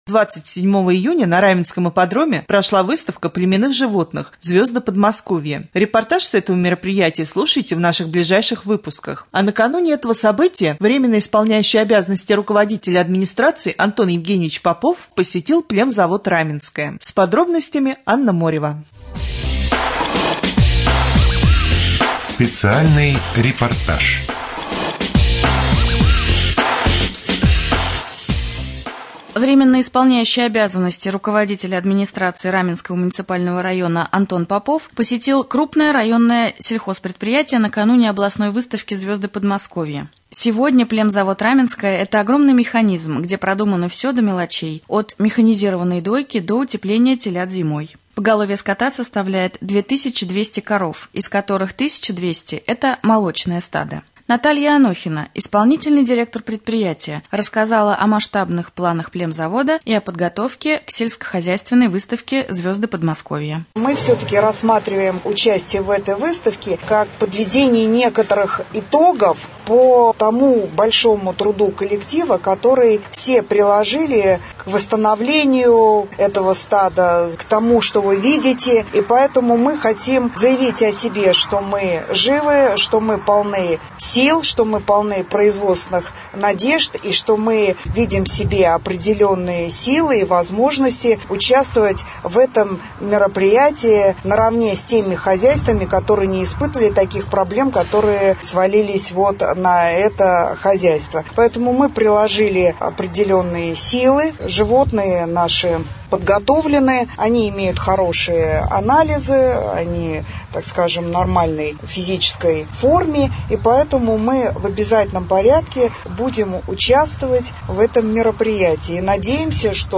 3.Рубрика «Специальный репортаж». Врио руководителя администрации Антон Евгеньевич Поповым посетил племзавод «Раменское».